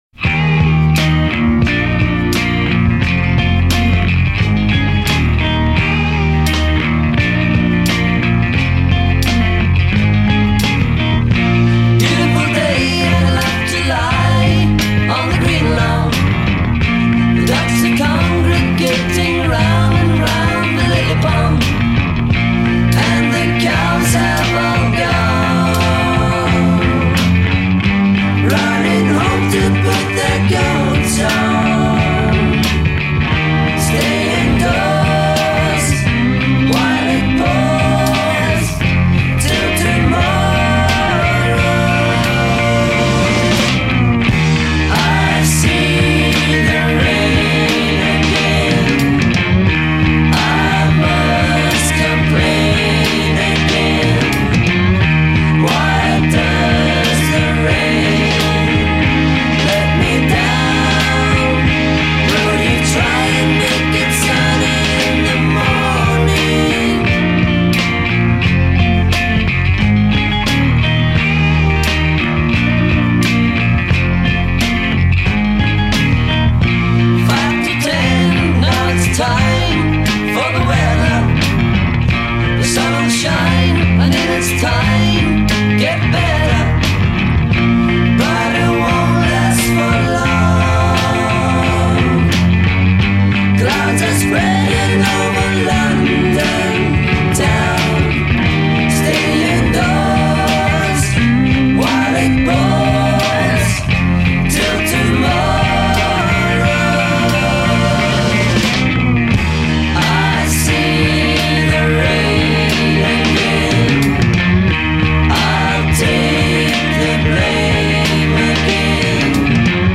is a great lost psych nugget with some fine harmonies